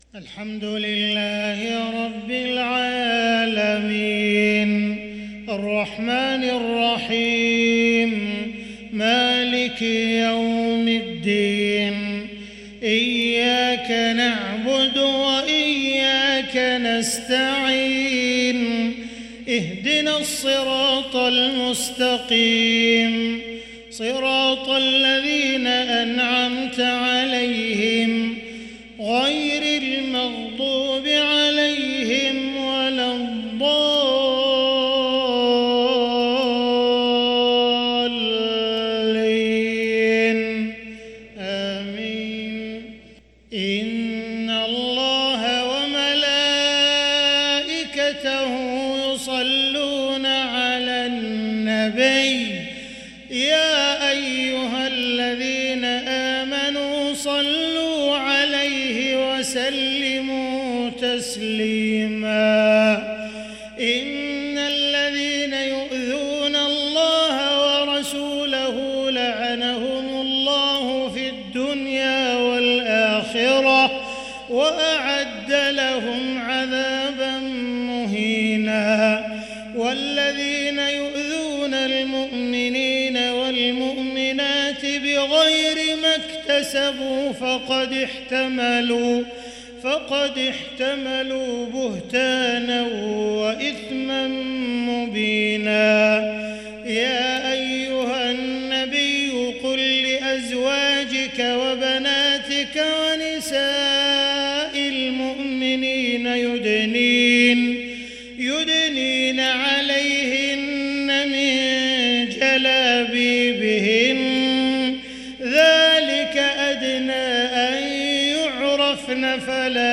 تلاوة رستية مذهلة للشيخ عبدالرحمن السديس من سورة الأحزاب - عشاء 26 رجب 1444هـ